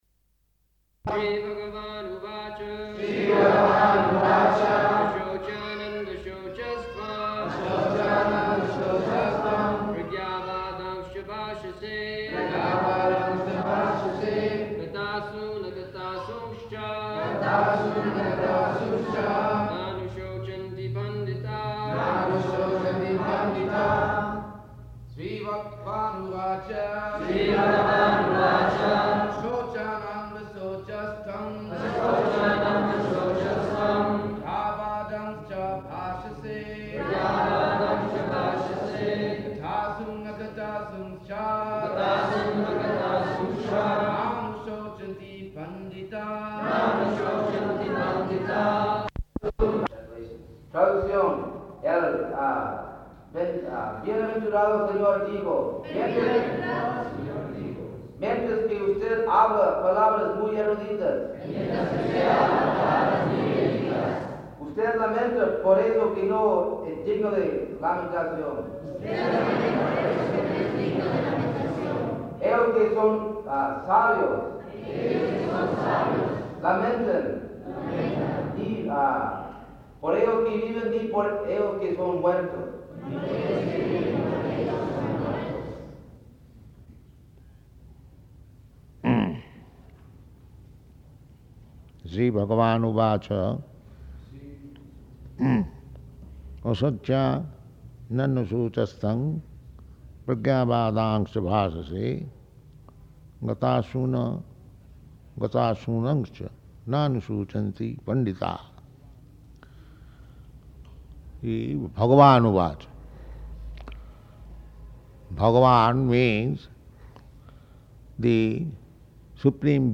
Location: Mexico City
[leads chanting of verse, etc.]
[translated throughout into Spanish]